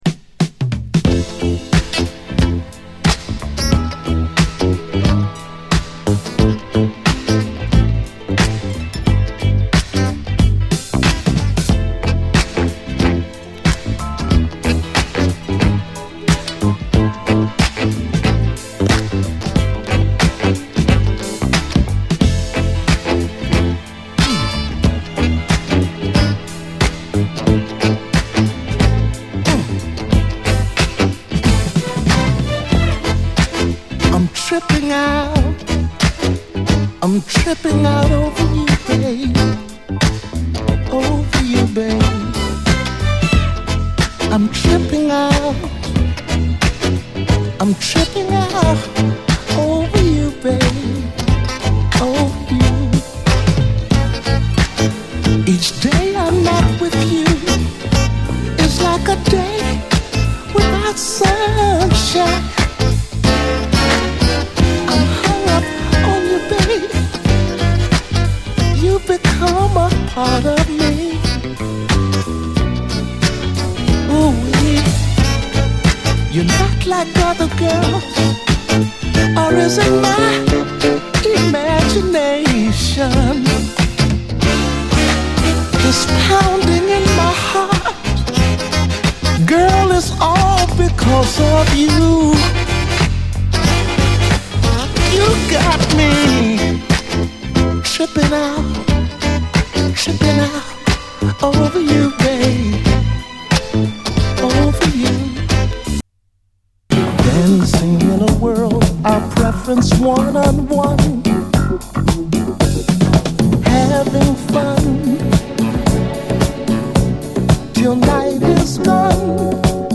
甘いソプラノ・サックスから一気に引き込まれるプリAOR